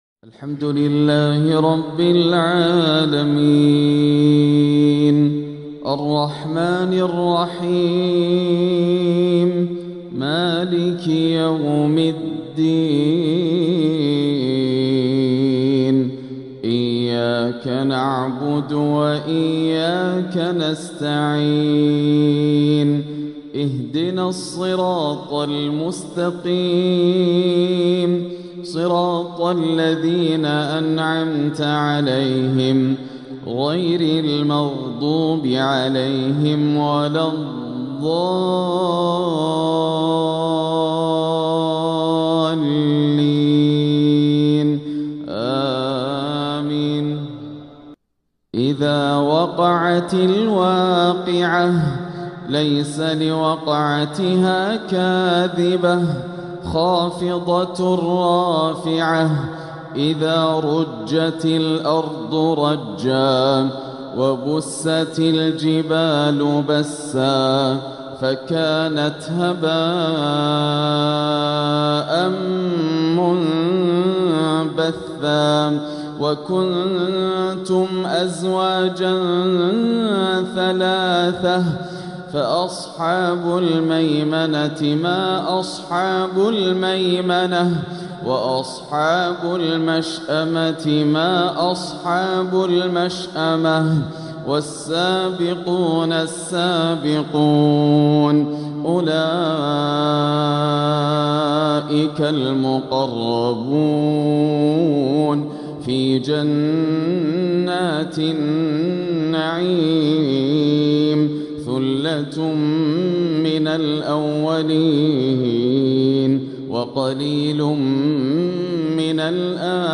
العقد الآسر لتلاوات الشيخ ياسر الدوسري تلاوات شهر ذو القعدة عام ١٤٤٦ هـ من الحرم المكي > سلسلة العقد الآسر من تلاوات الشيخ ياسر > المزيد - تلاوات ياسر الدوسري